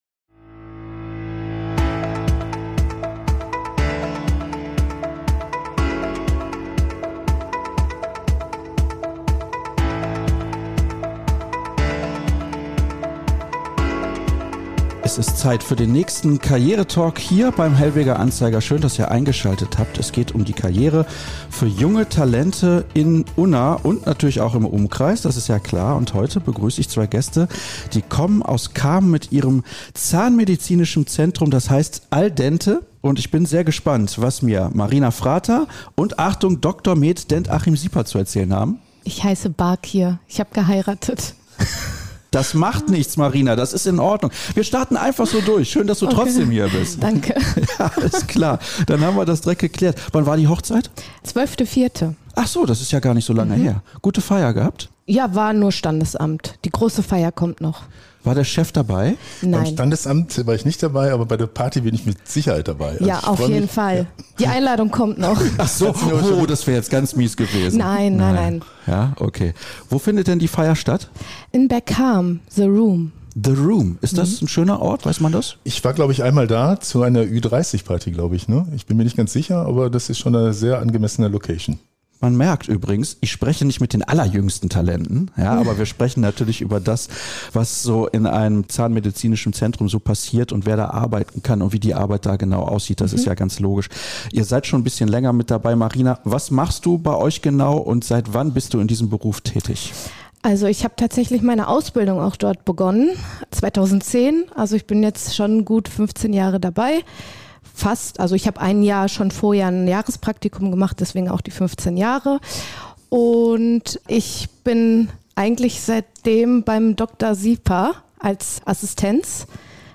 Karriere-Talk